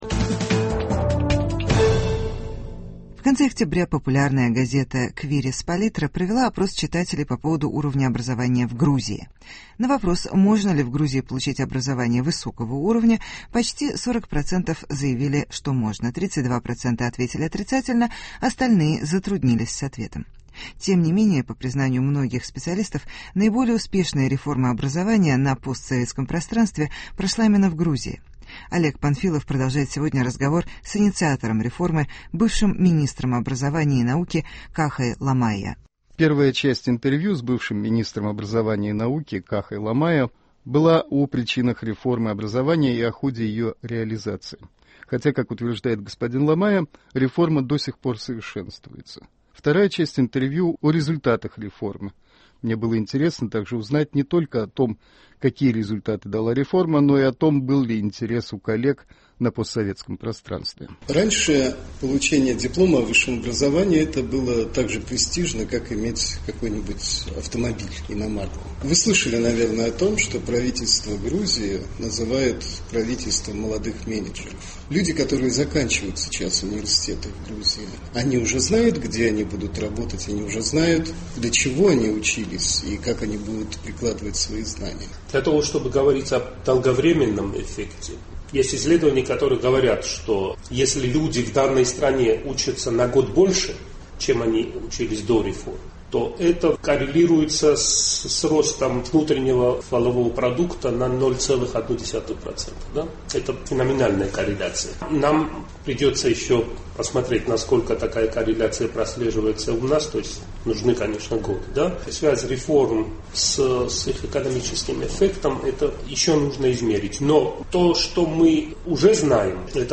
Новое лицо Грузии: интервью с секретарем Совета Безопасности, ч. 2.